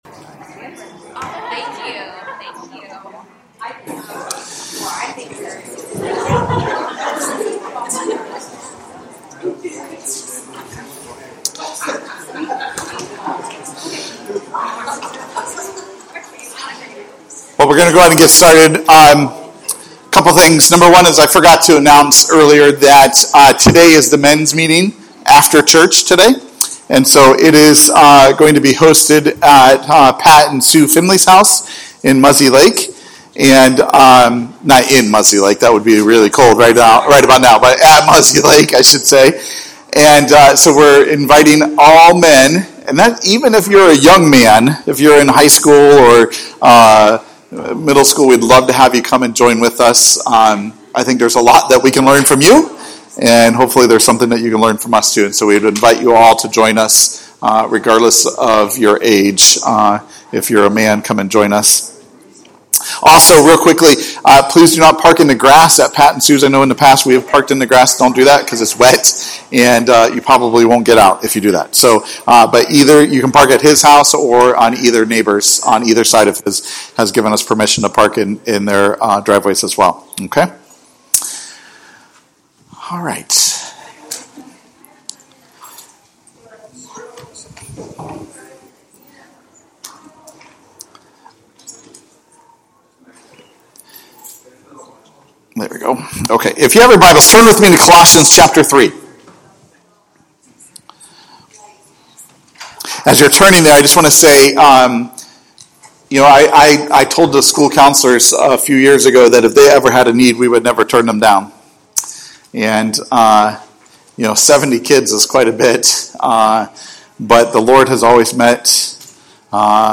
Sermons by Passion Community Church